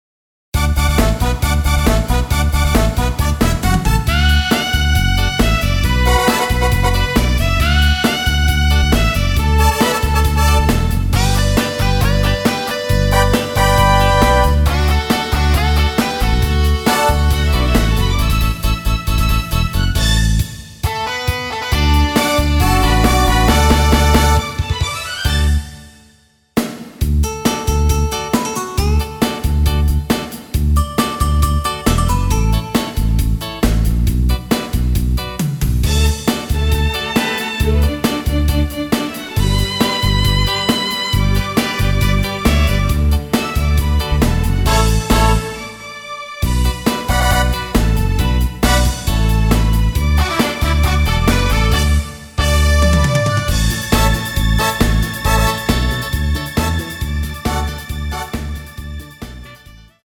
D
앞부분30초, 뒷부분30초씩 편집해서 올려 드리고 있습니다.